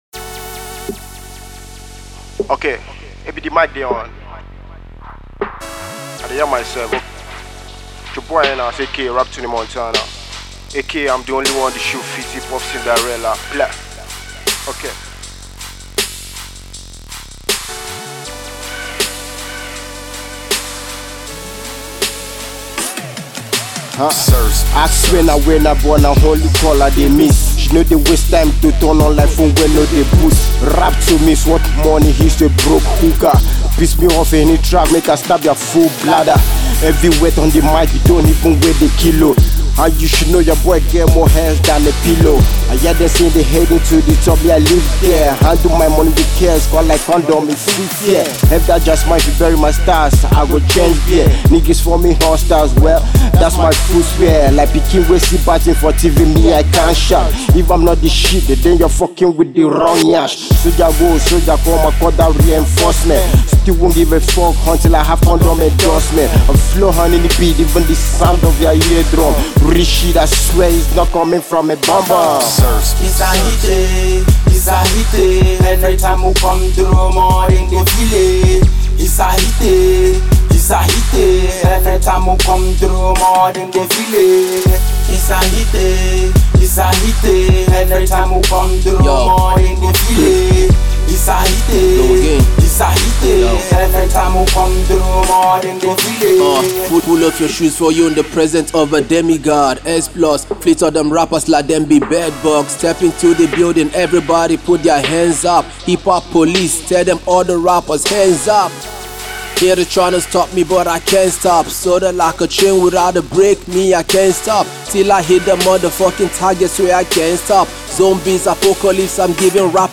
a monster beat